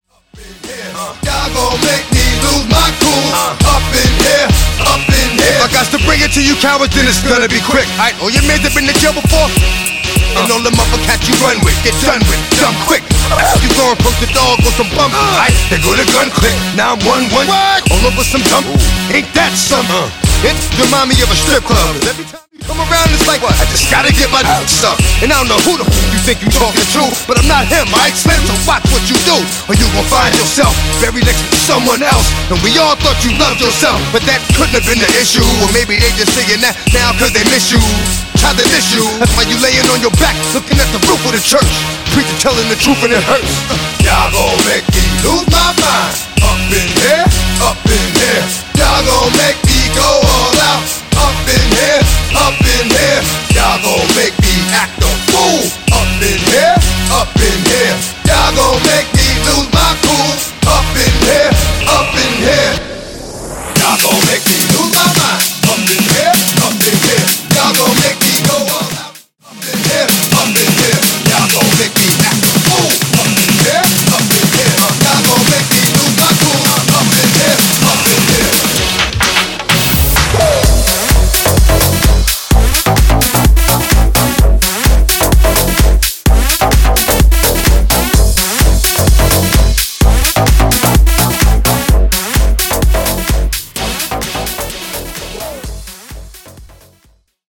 Genres: BOOTLEG , DANCE , R & B Version: Clean BPM: 122 Time